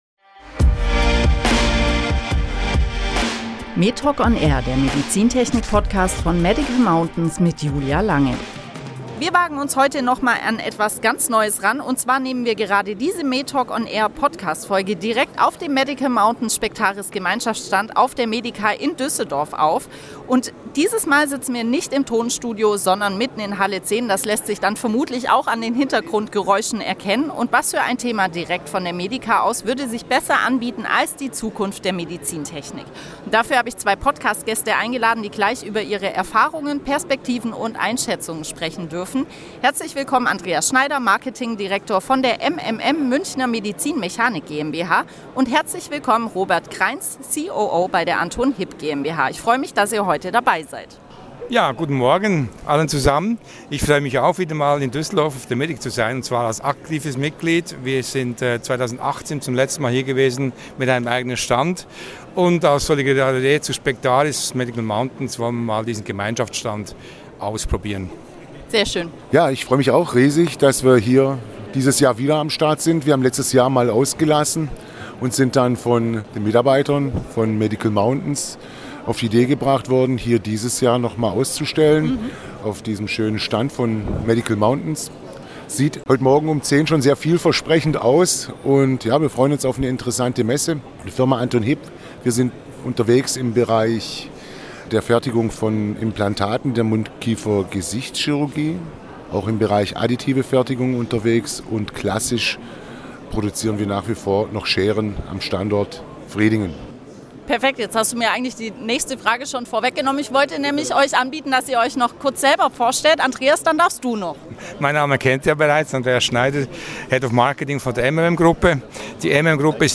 Mitten im Messetrubel der diesjährigen MEDICA in Düsseldorf sprechen wir über die Zukunft der Medizintechnik, einen passenderen Ort für dieses Thema gibt es sonst kaum.